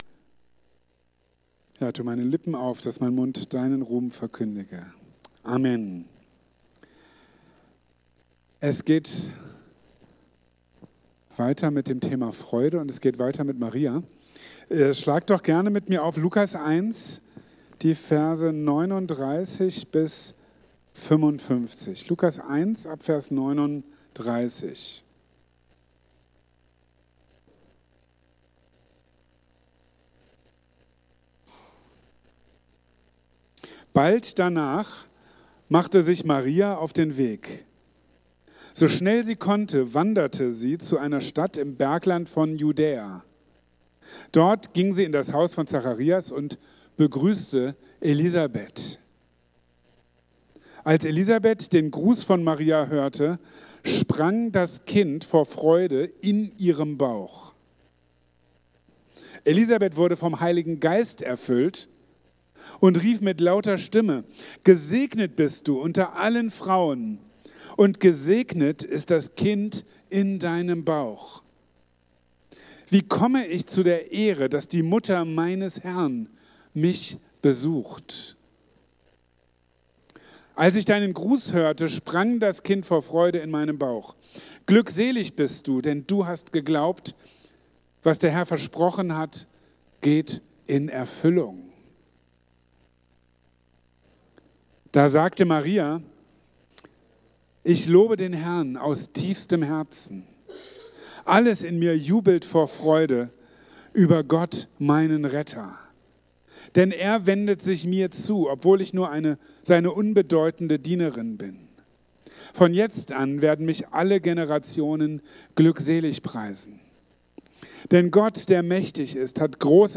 Fünfmal Freude | Marburger Predigten